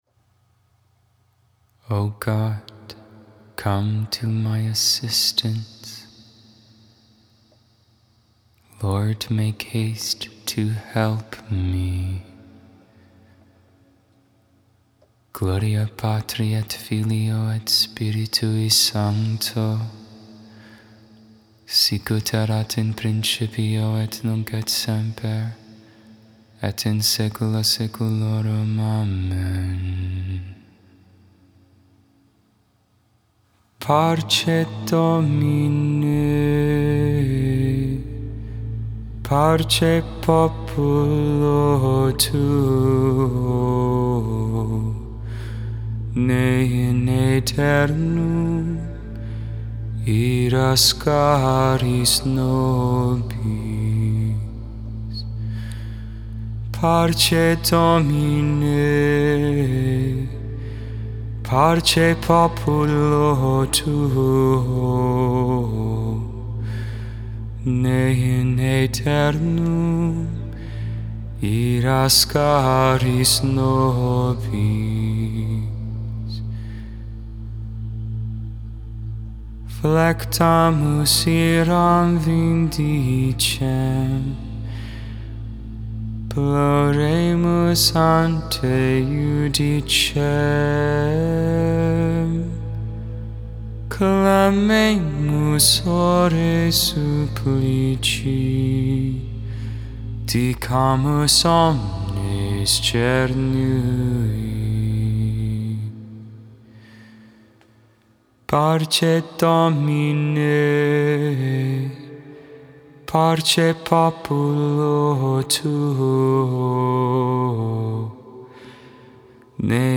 4.6.22 Vespers, Wednesday Evening Prayer